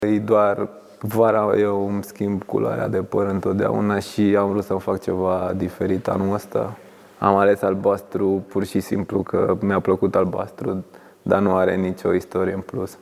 „Toți jucătorii sunt buni, dar credem în șansa noastră”, spune fundașul Andrei Rațiu, într-un interviu pentru FRF TV.